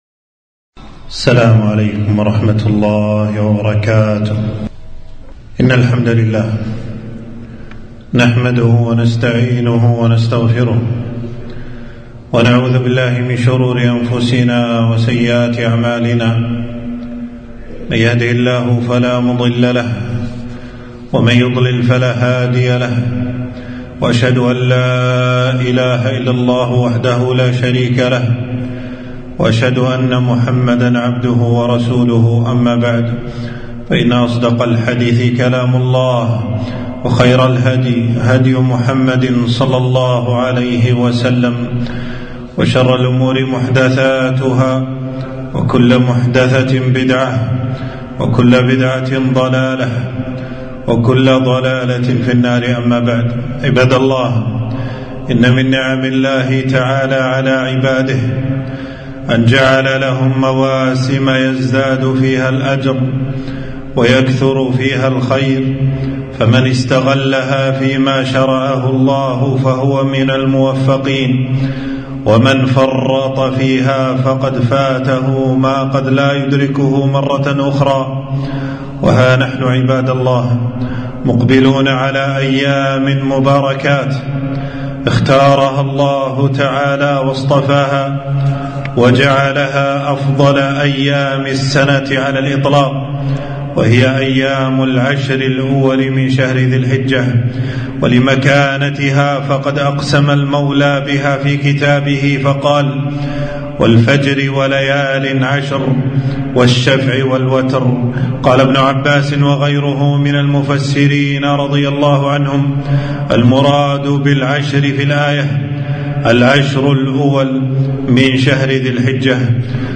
خطبة - العَشْرُ الأُوَلُ مِنْ ذِي الحِجَّةِ ومَكَانَة العُلَمَاء